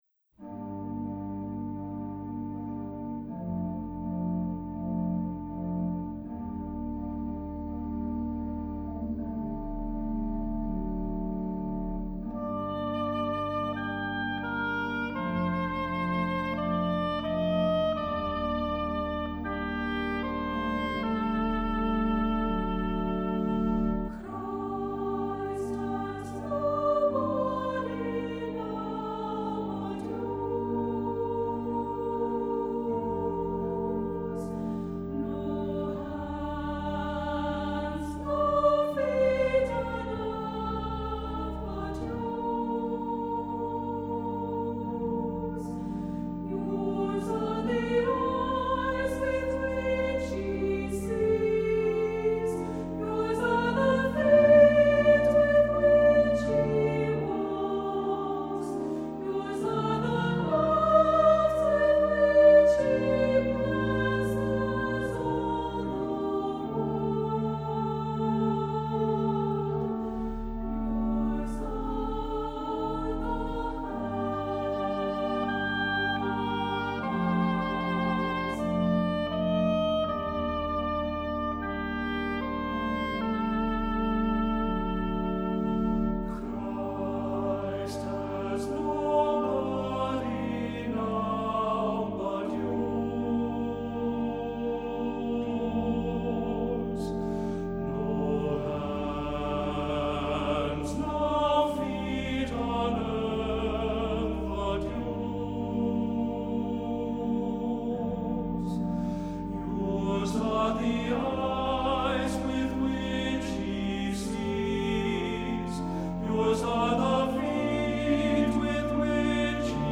Accompaniment:      With Organ, C Instrument
Music Category:      Christian